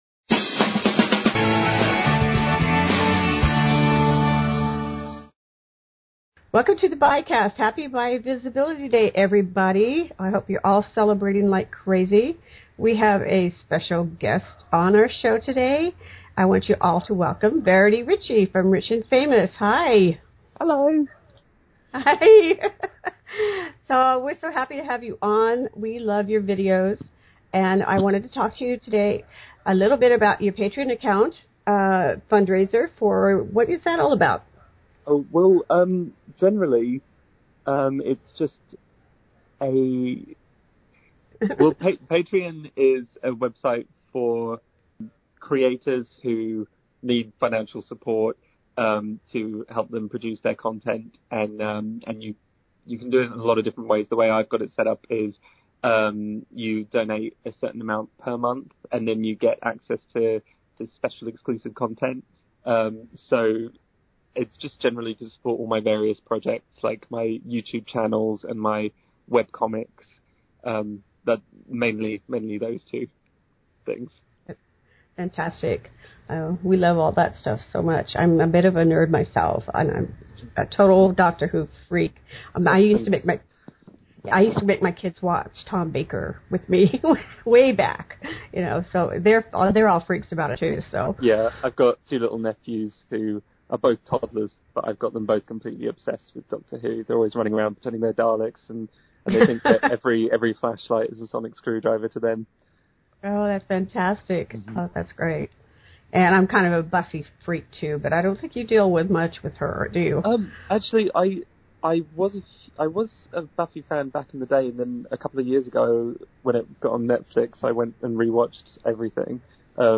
Interview Interview